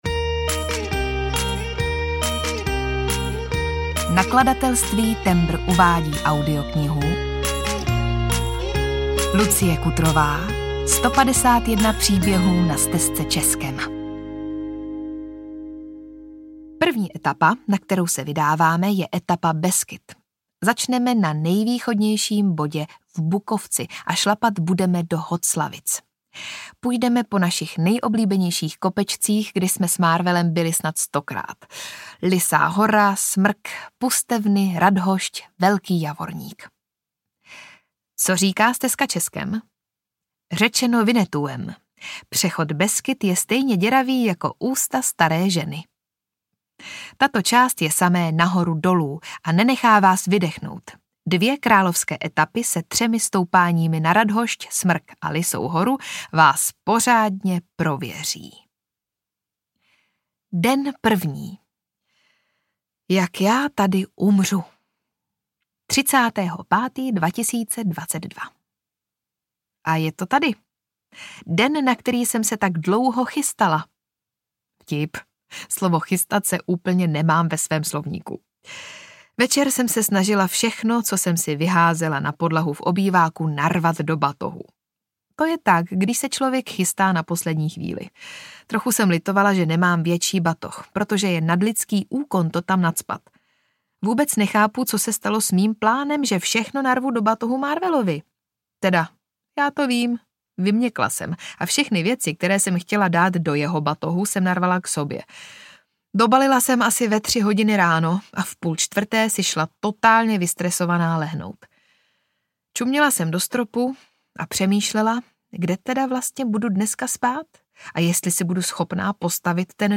151 příběhů na Stezce Českem audiokniha
Ukázka z knihy